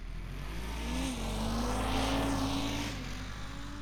Snowmobile Description Form (PDF)
Subjective Noise Event Audio File - Run 1 (WAV)